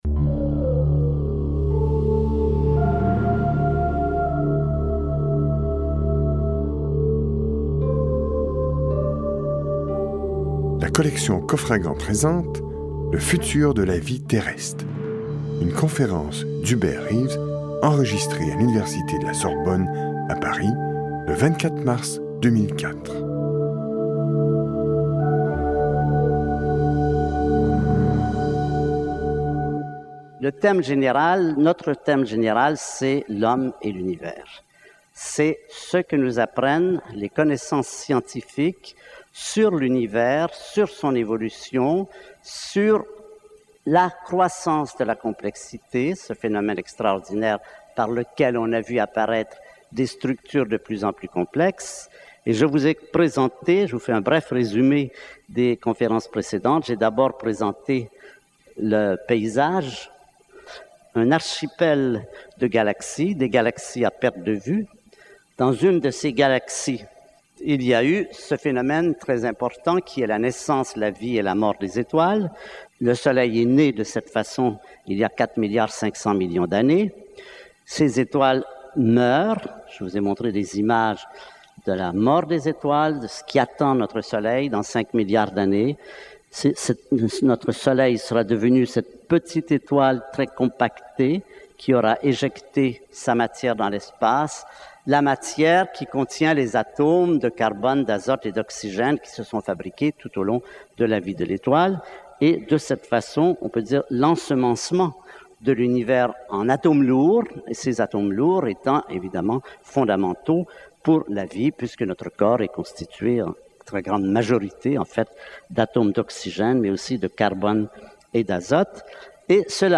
Diffusion distribution ebook et livre audio - Catalogue livres numériques
Notre planète va mal : réchauffement climatique, épuisement des ressources naturelles, pollution des sols et de l’eau provoquée par les industries civiles et guerrières, disparité des richesses, malnutrition des hommes, taux d’extinction effarant des espèces vivantes, etc. Lu par Hubert Reeves